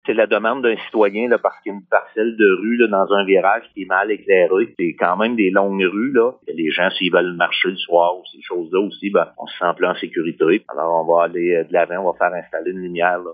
Le maire d’Egan-Sud, Neil Gagnon, mentionne également que l’installation sera faite afin d’assurer un sentiment de sécurité pour les citoyens qui désirent marcher dans ce secteur.